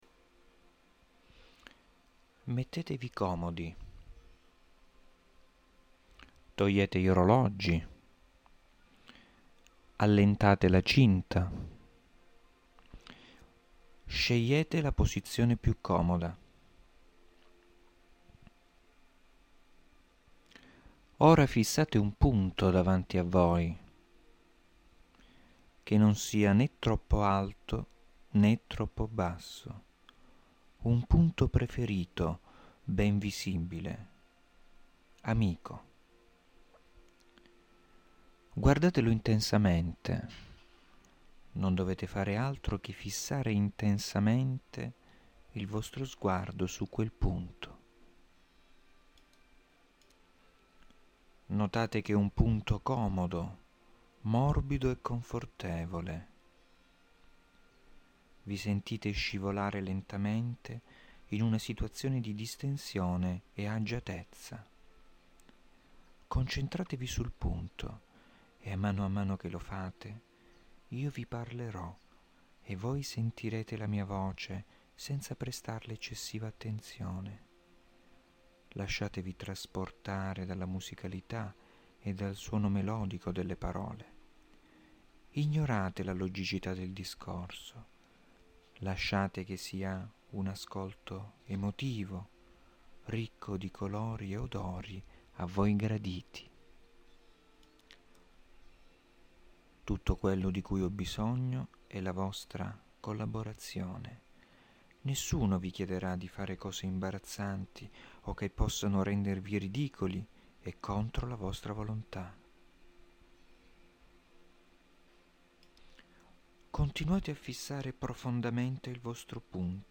Esercizi di rilassamento
in questa area troverà alcuni esercizi di rilassamento da me registrati, comprendenti una induzione iniziale, una suggestione ed una visualizzazione (fantasia guidata).
• Quarto esempio di induzione, Sedia.